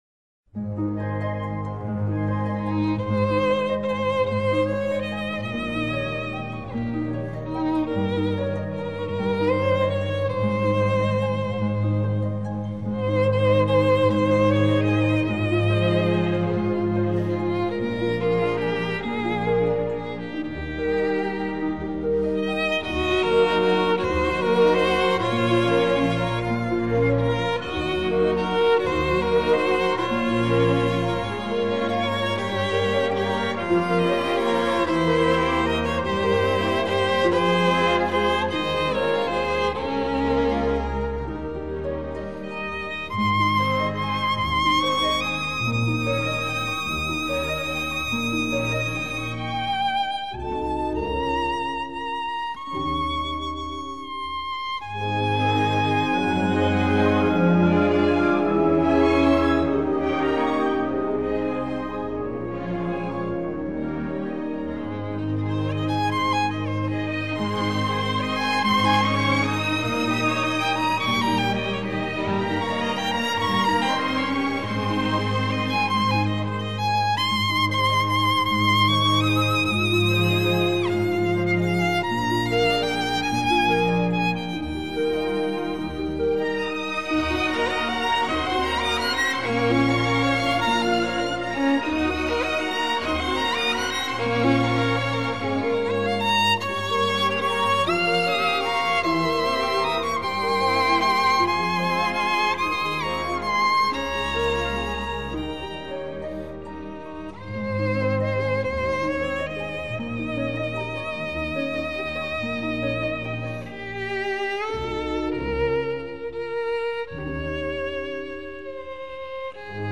内  容： 世界古典名曲 Highlights